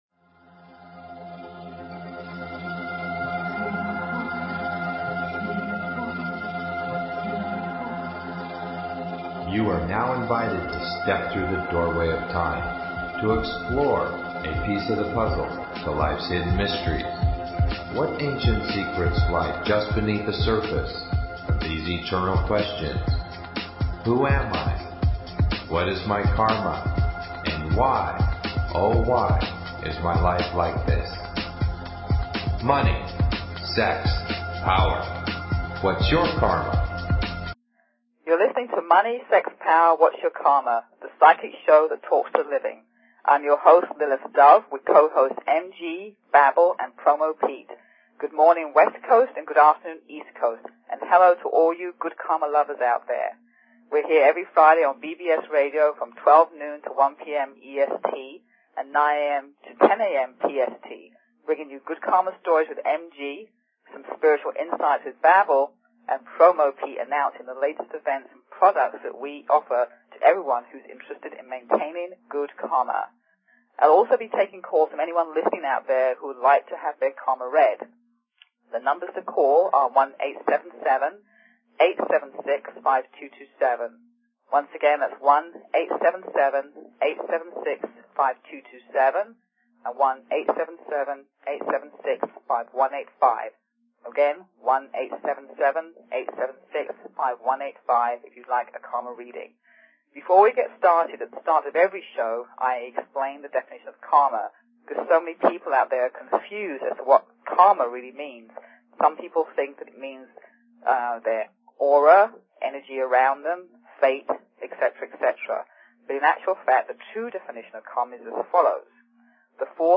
Talk Show Episode, Audio Podcast, Money_Sex_Power_Whats_Your_Karma and Courtesy of BBS Radio on , show guests , about , categorized as
LIVE call-in Karma Readings